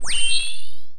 sci-fi_power_up_02.wav